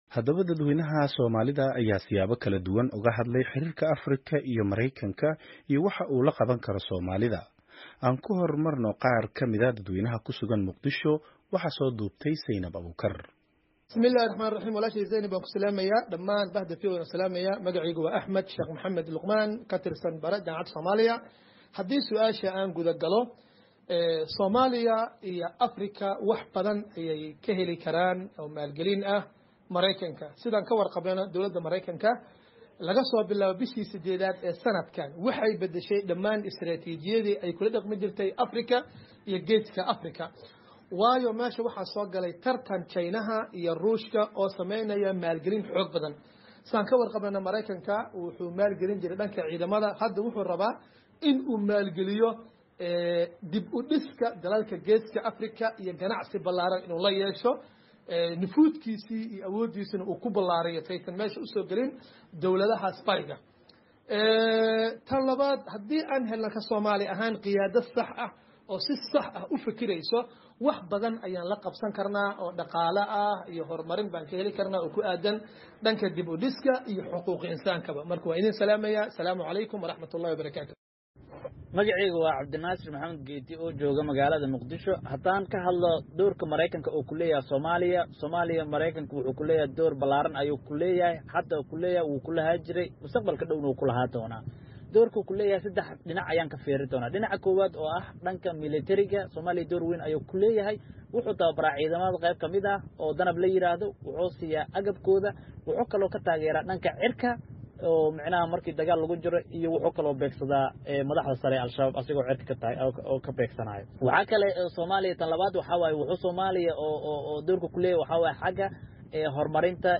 Haddaba dadweynaha Soomaalida ayaa siyaabo kala duwan uga hadlay xiriirka Afrika iyo Maraykanka, iyo waxa uu la qaban karo Soomaalida.